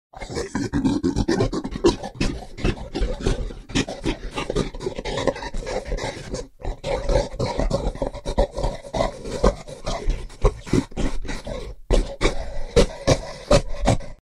Звуки гориллы
Голос гориллы